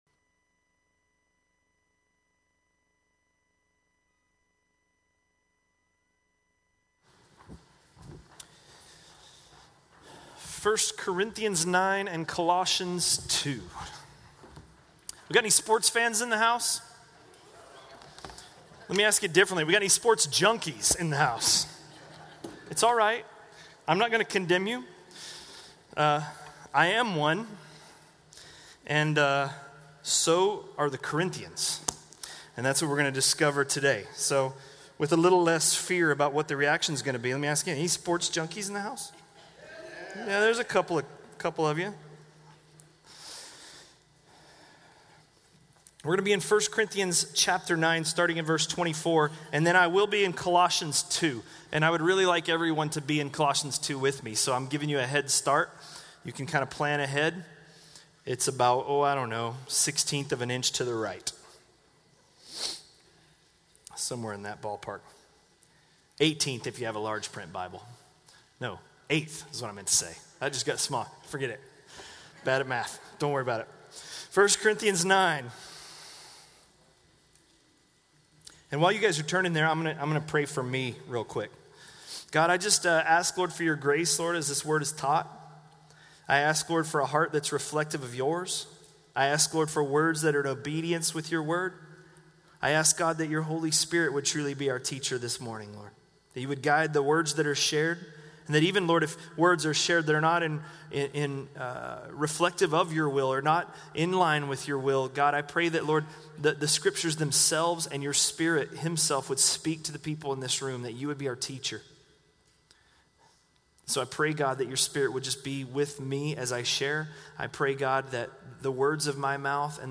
A message from the series "1 Corinthians." 1 Corinthians 9:24–9:27